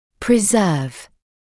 [prɪ’zɜːv][при’зёːв]сохранять, сберегать